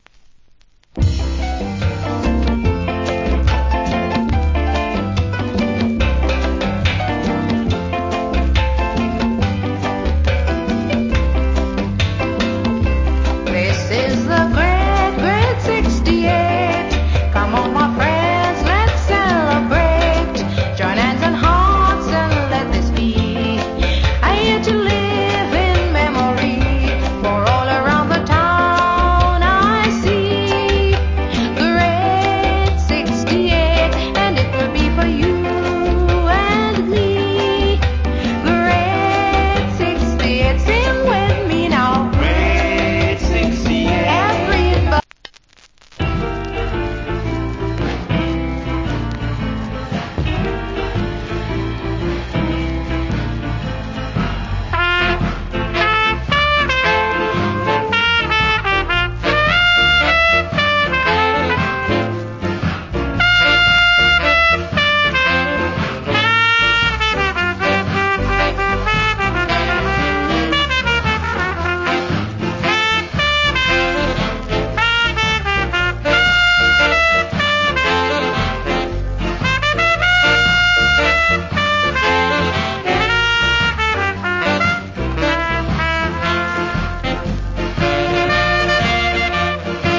Sweet Female Calypso Vocal.